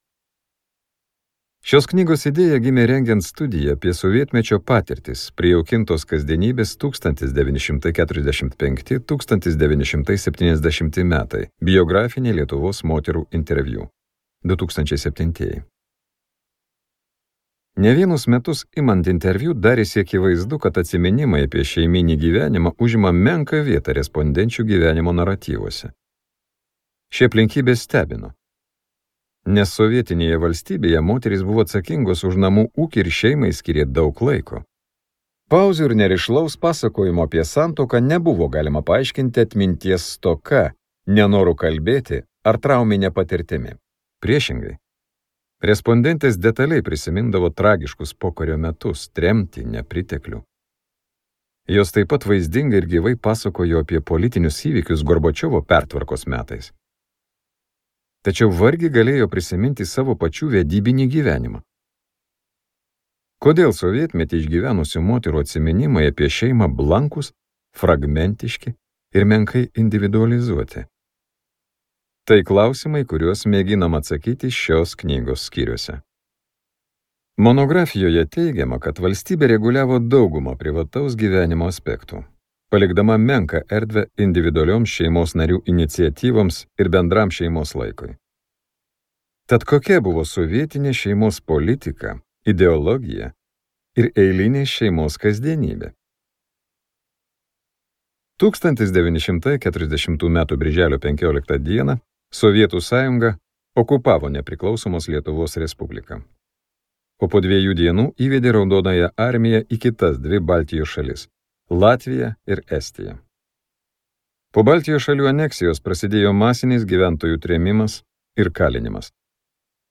Skaityti ištrauką play 00:00 Share on Facebook Share on Twitter Share on Pinterest Audio Neplanuotas gyvenimas.